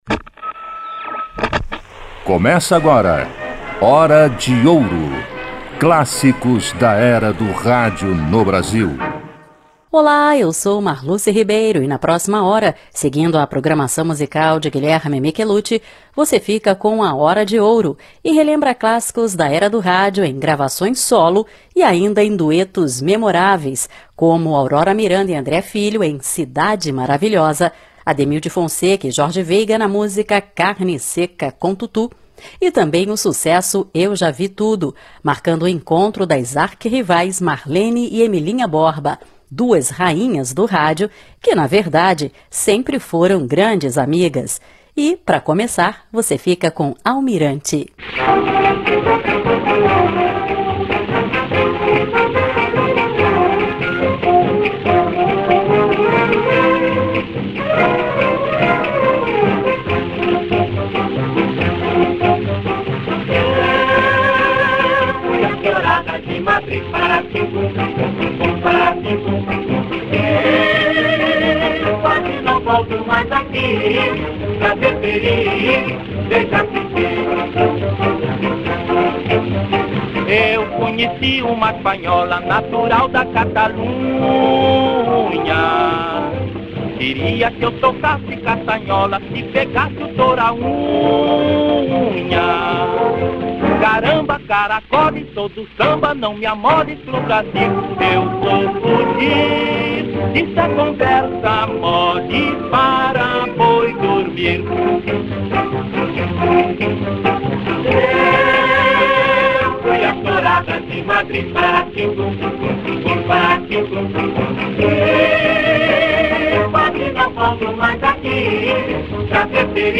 você ouve alguns duetos memoráveis